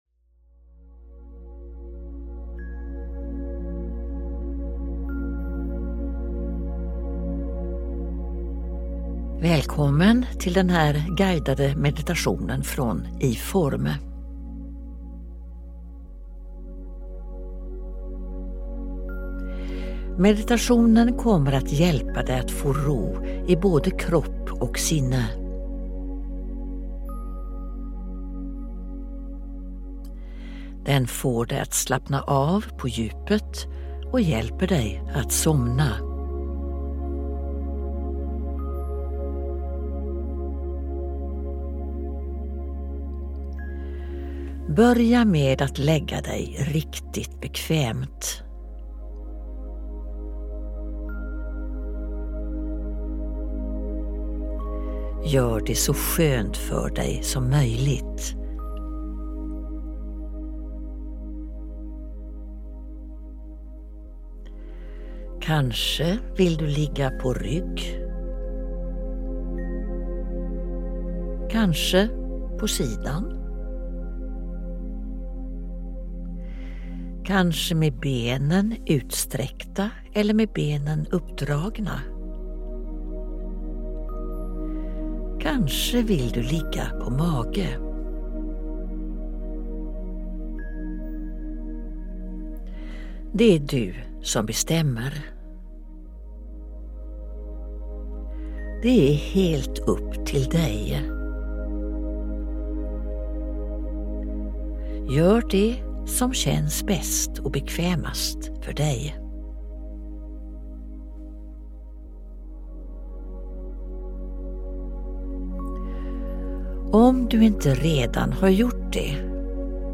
Guidad sömnmeditation: Släpp taget och sov gott
Den avslutande kroppsscanningen gör att du känner dig tung och helt lugn. Du blir guidad hela vägen – allt du behöver göra är att lägga dig tillrätta och lyssna.
De sista fem minuterna innehåller inget prat, utan är bara en fortsättning på det lugna ljudspåret.